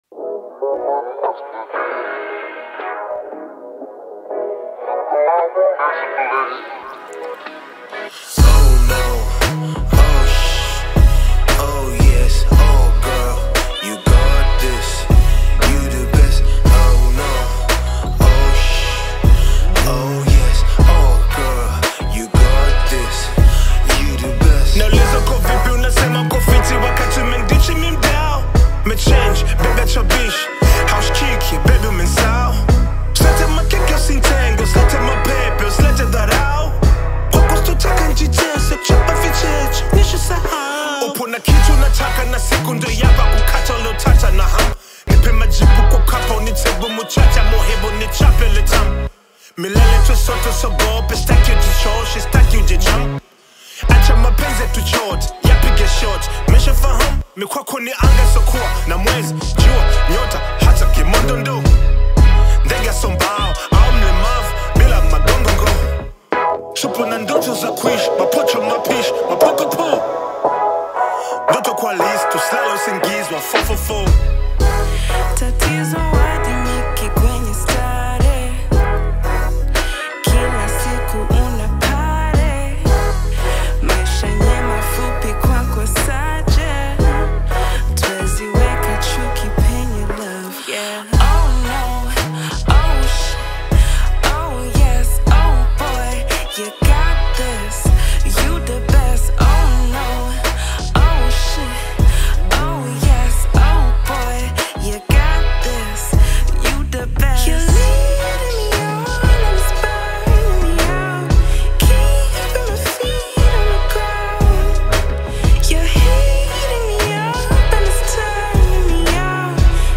AudioBongo Hip-HopTanzanian Music